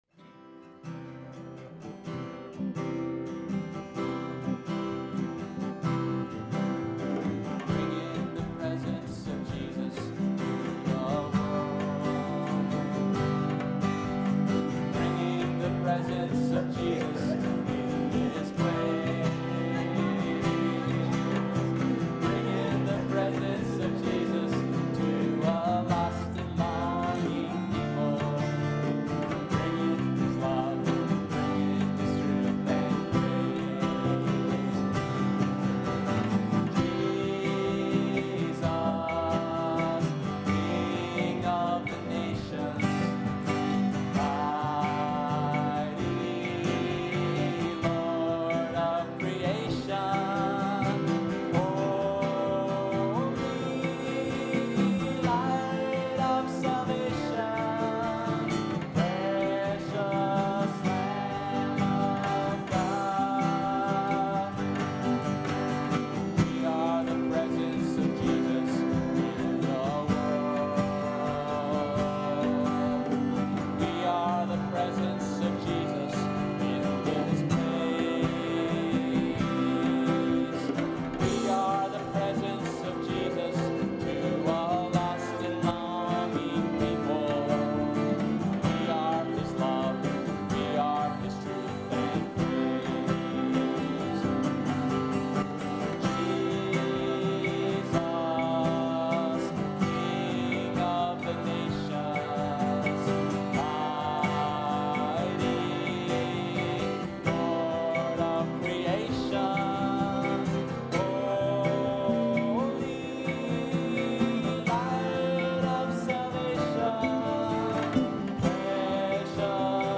(a light calypso, sung as a two part round)